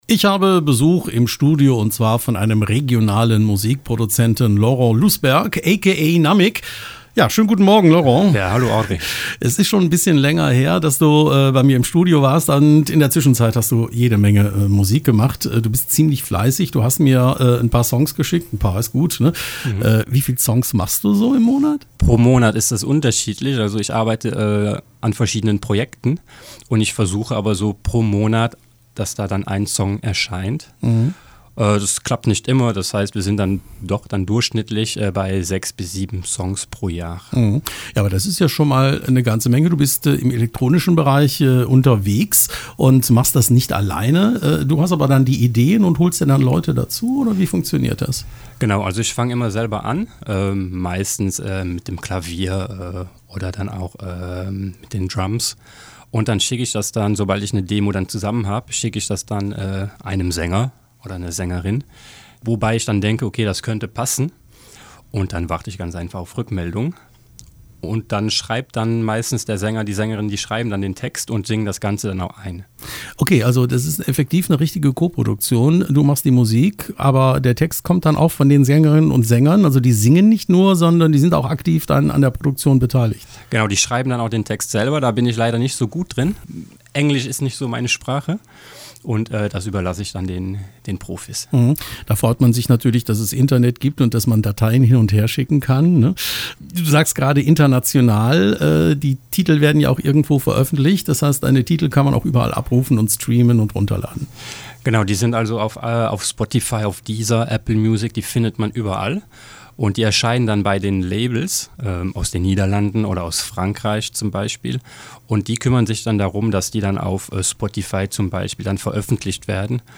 Hier gibt es das Interview: